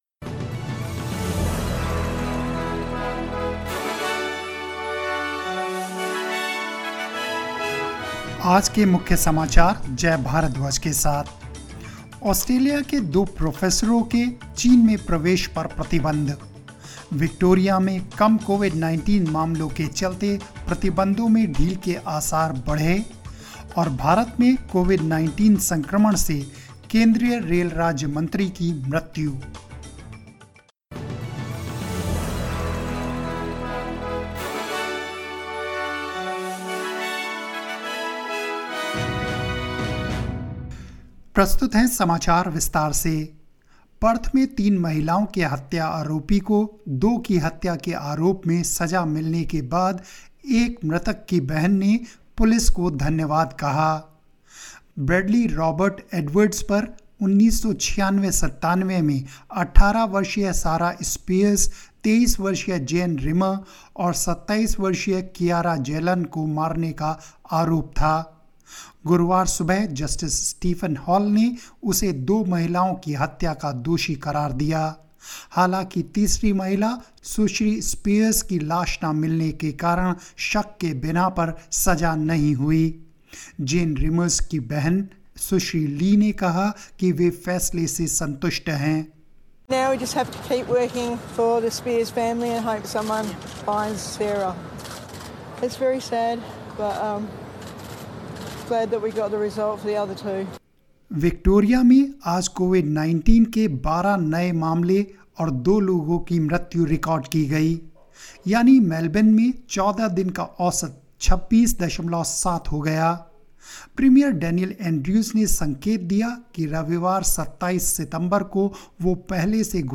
News in Hindi 24 September 2020